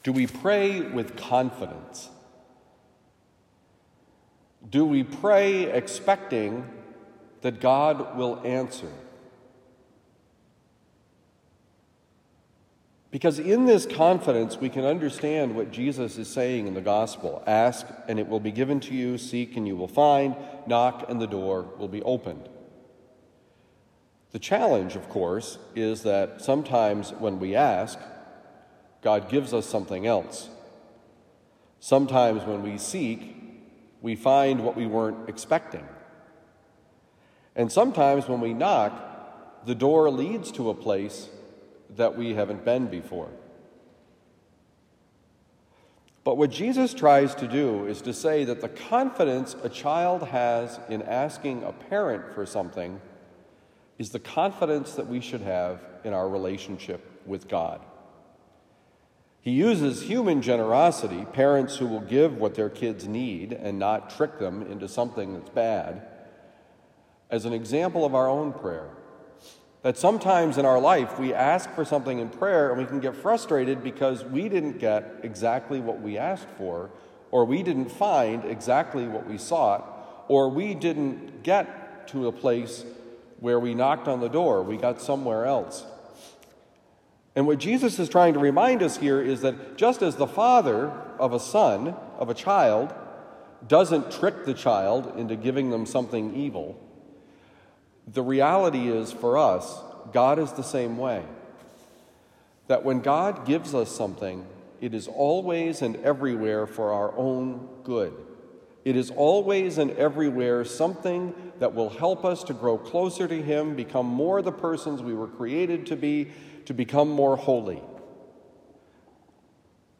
Confidence in prayer: Homily for Thursday, March 2, 2023
Given at Christian Brothers College High School, Town and Country, Missouri.